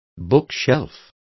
Complete with pronunciation of the translation of bookshelf.